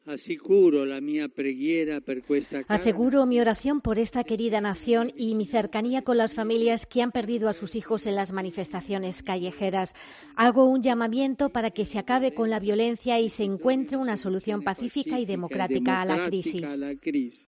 "Hago un llamamiento para que se acabe con la violencia y se encuentre una solución pacífica y democrática a la crisis", añadió el Papa Francisco  ante los fieles congregados en la Plaza de San Pedro que asistieron al tradicional rezo del Ángelus.